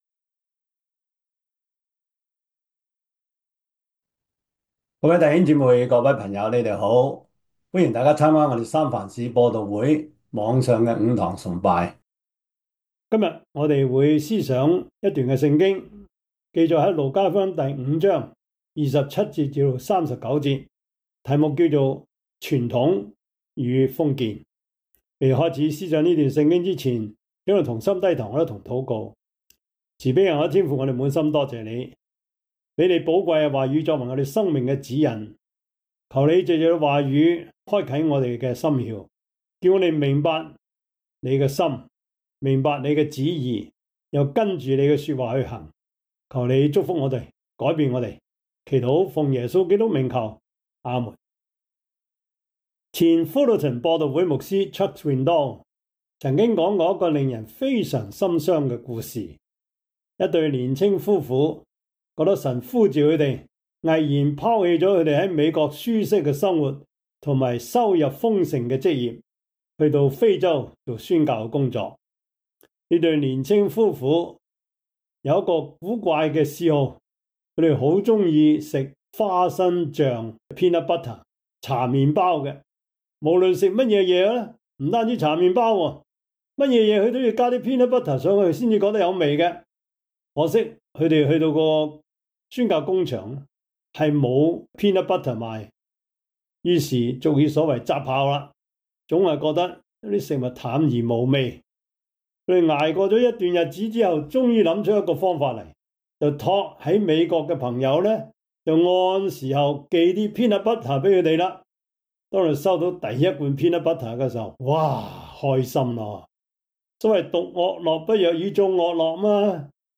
Service Type: 主日崇拜